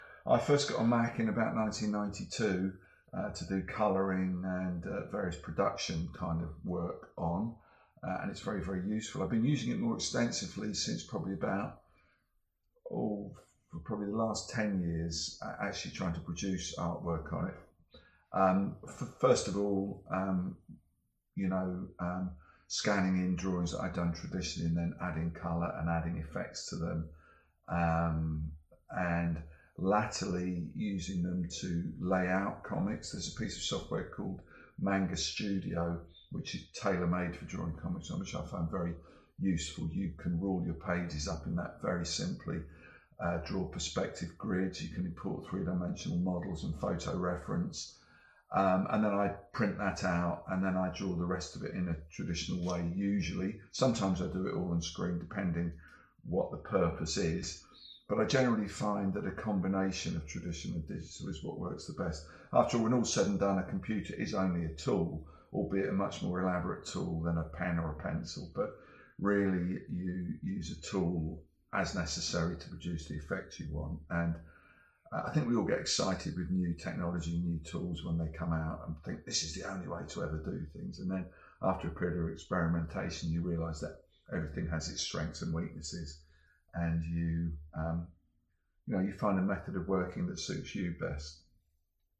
Dave Gibbons interview: How long have you been drawing on a Mac?